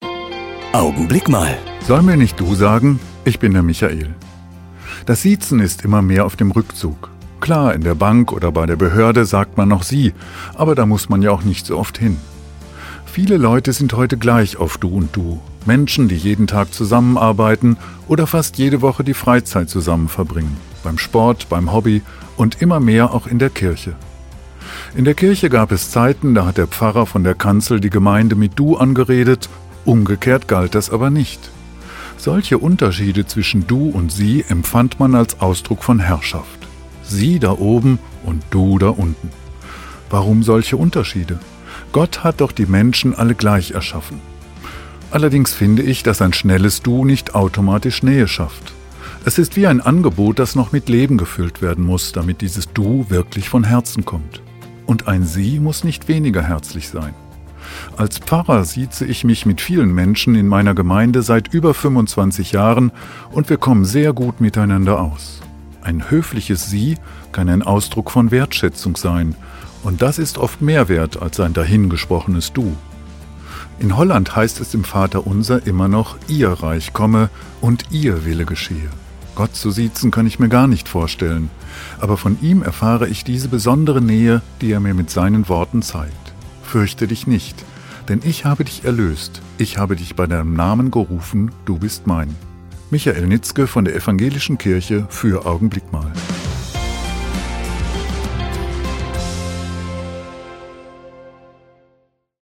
Radioandachten